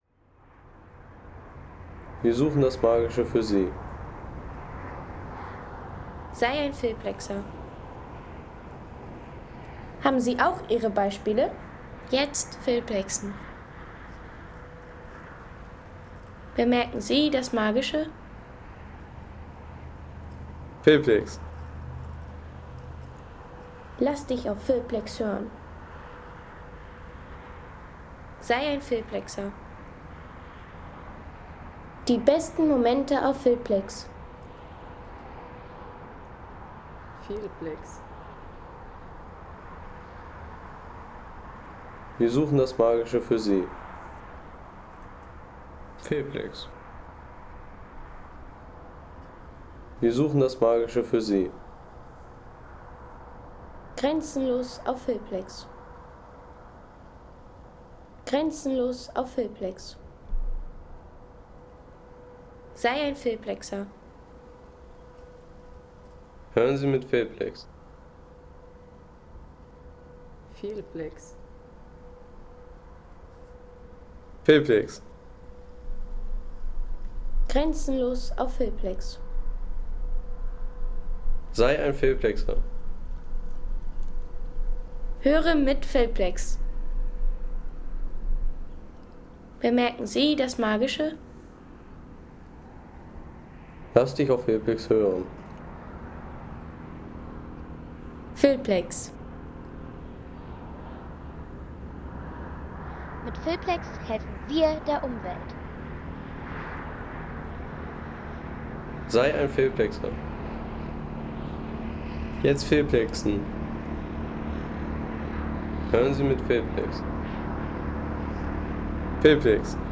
Landschaft - Berge
Entspannen mit Aussicht – Lauschen Sie der Weinbergidylle an der Mos ... 4,50 € Inkl. 19% MwSt.